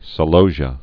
(sə-lōzhə, -zhē-ə)